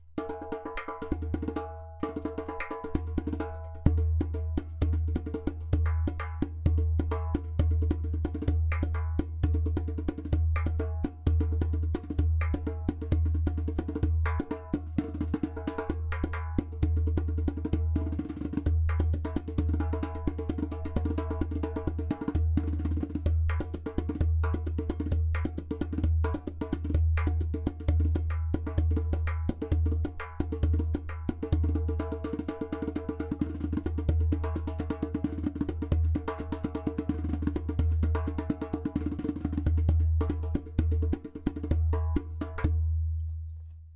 Premium Earth Bass 27cm Dohola with Goat skin
130bpm
• Strong and super easy to produce clay kik (click) sound
• Deep bass
• Beautiful harmonic overtones.